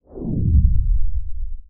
TIME_WARP_Stop_01_mono.wav